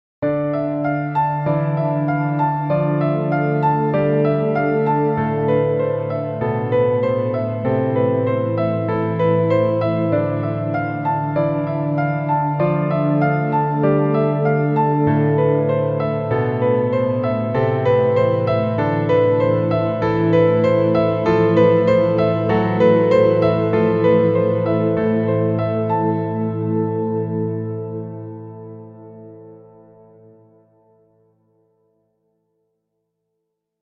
Music logo or opening theme song.
Genres: Sound Logo